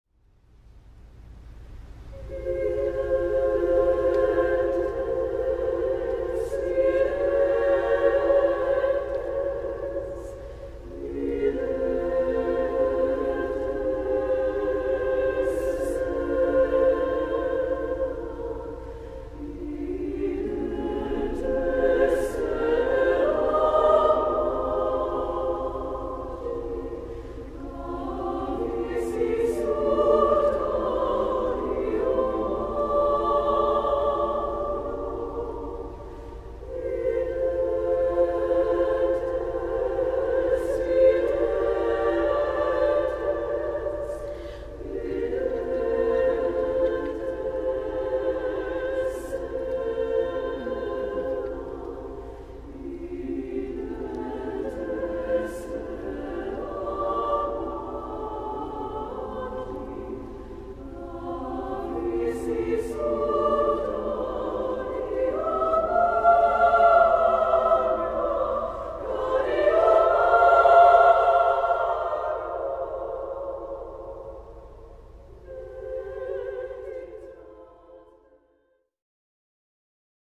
• Voicing: ssaa
• Accompaniment: a cappella